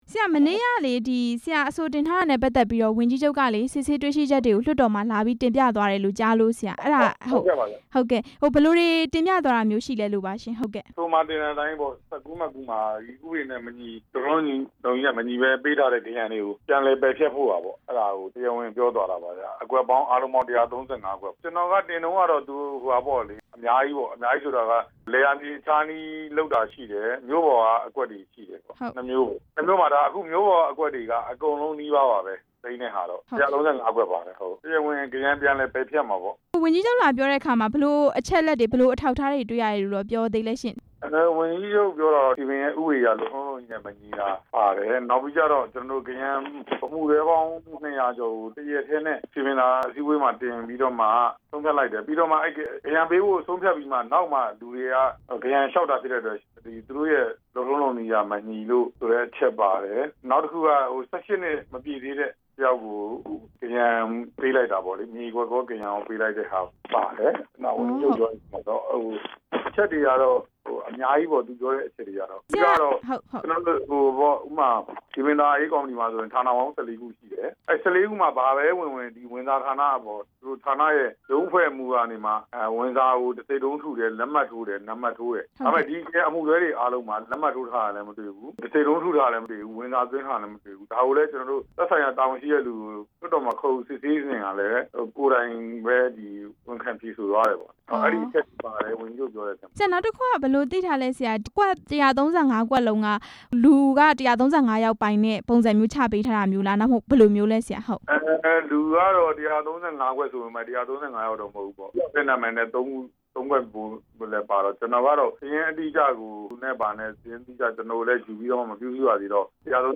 မန္တလေးတိုင်းက အများပြည်သူပိုင်မြေတွေ သိမ်းယူမယ့်ကိစ္စ မေးမြန်းချက်